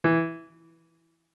MIDI-Synthesizer/Project/Piano/32.ogg at 51c16a17ac42a0203ee77c8c68e83996ce3f6132